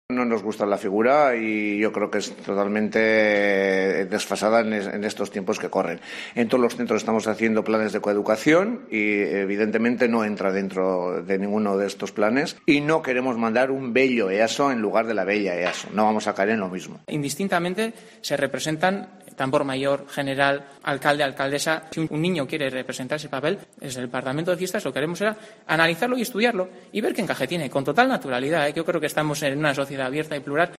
edil de Fiestas.